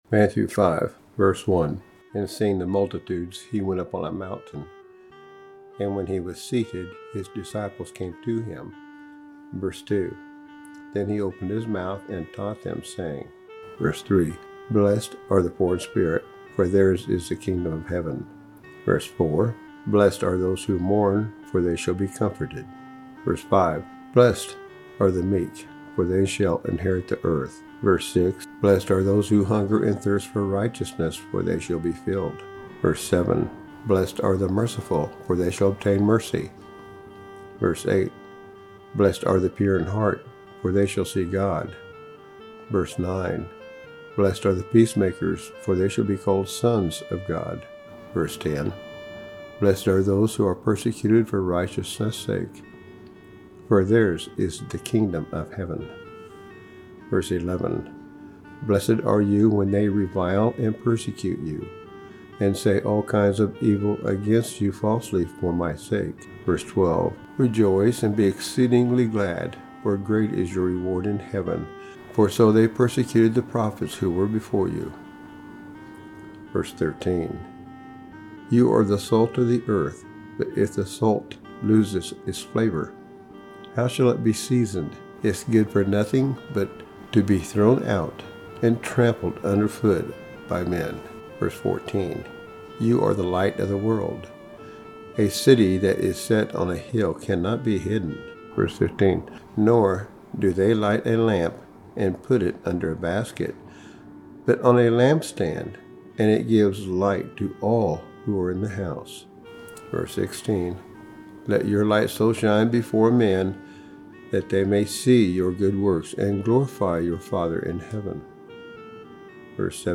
Unfolding the Bible: Matthew Chapter 5 By The Park Prison Ministry on January 30, 2026 | Unfolding the Bible Audio Reading of Matthew Chapter 5…